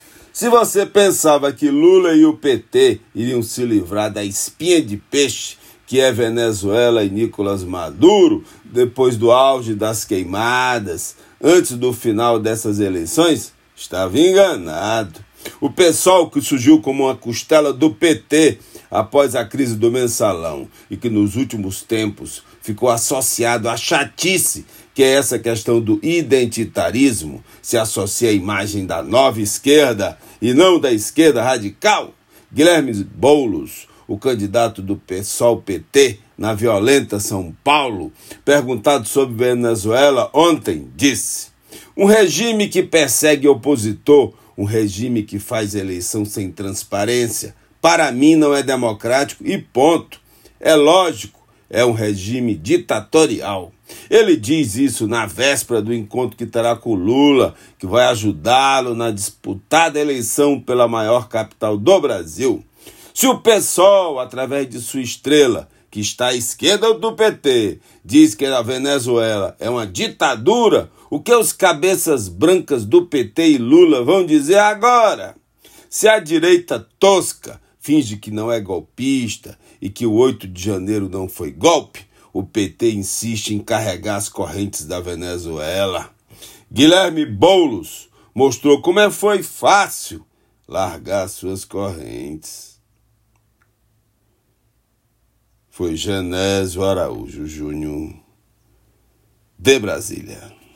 Comentário desta sexta-feira (27/09/24)
direto de Brasília.